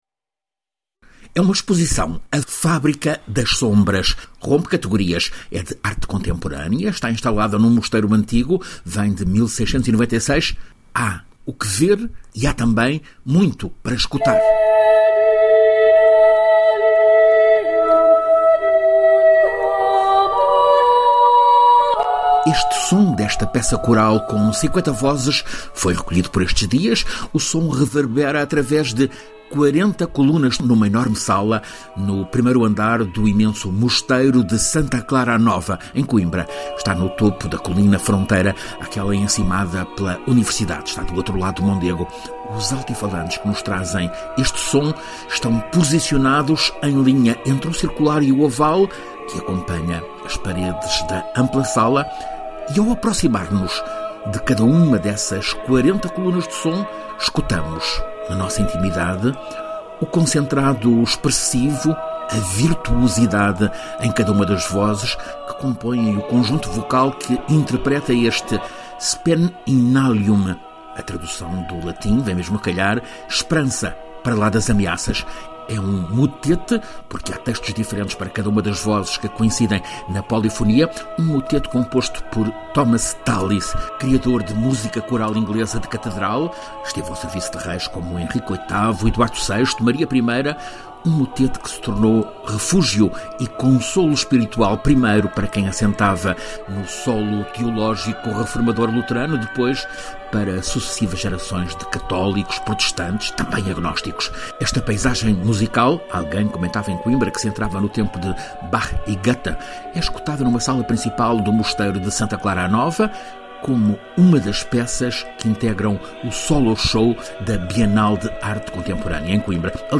Este som desta peça coral com 50 vozes foi recolhido por estes dias. O som reverbera através de 40 colunas de som numa enorme sala no 1º andar do imenso Mosteiro de Santa Clara-a-Nova, em Coimbra.